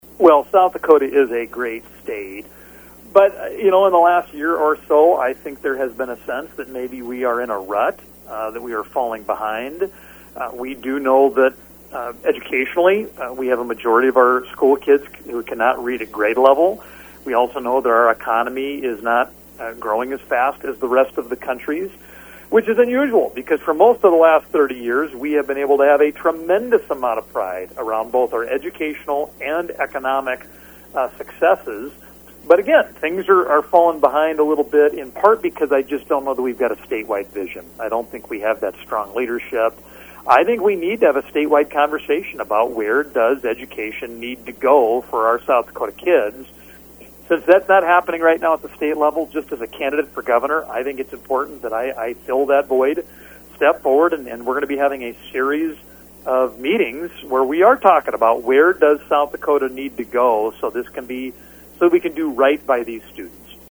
Johnson spoke with local education leaders at a roundtable discussion Monday in Huron.